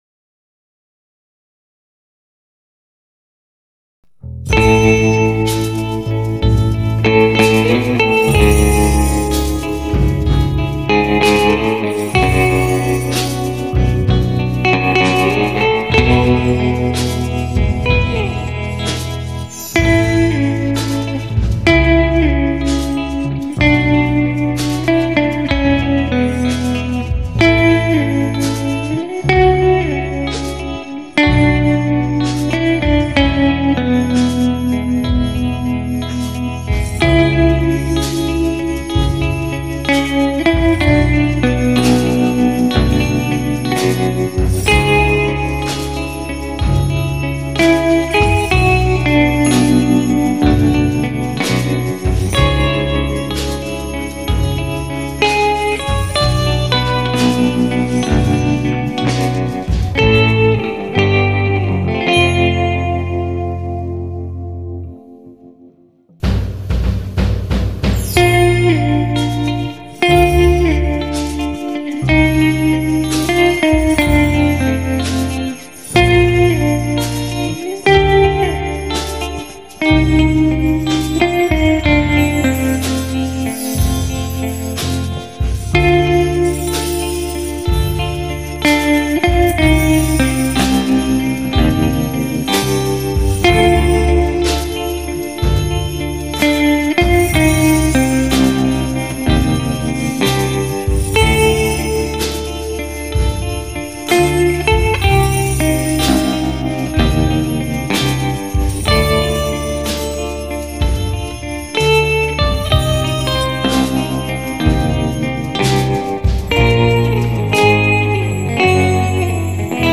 The melody is awesome
I did the Bass guitar, the Baritone guitar and lead guitar
The lead guitar is my Jazzmaster